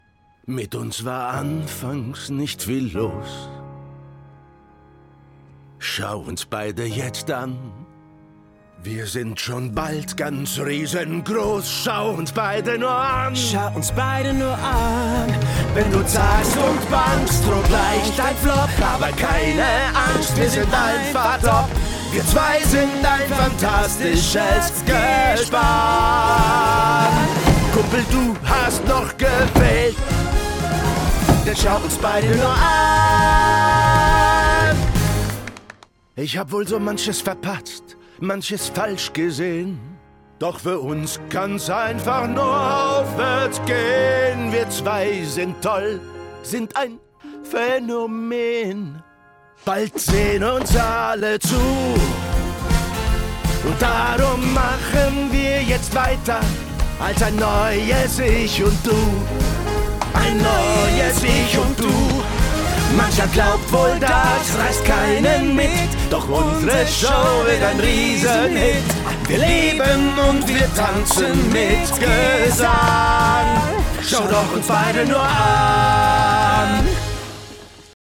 Locutores
Nativo